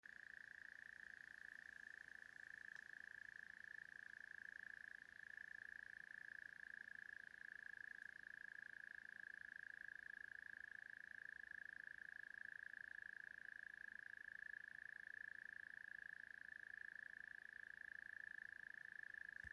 Zemesvēzis, Gryllotalpa gryllotalpa
Administratīvā teritorijaDaugavpils novads
Piezīmes/Dzied